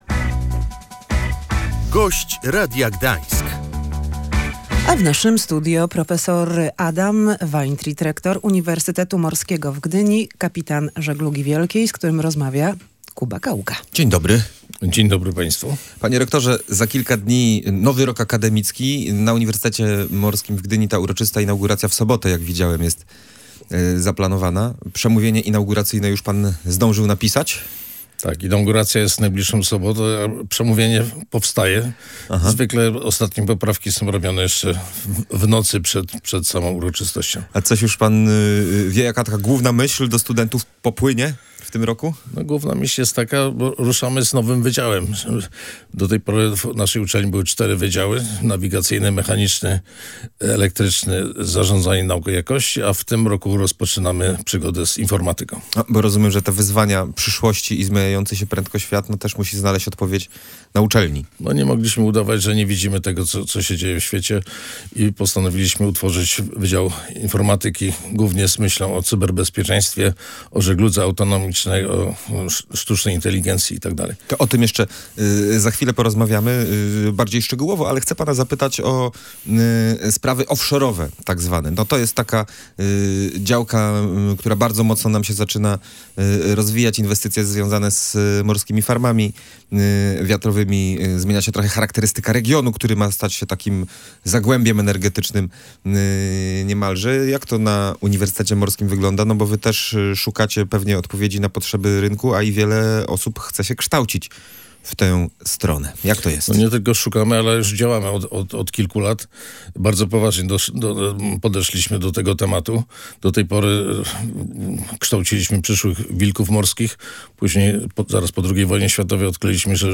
W ciągu kilku lat musi powstać jednostka, która zastąpi Dar Młodzieży - mówił w Radiu Gdańsk prof. dr hab. inż.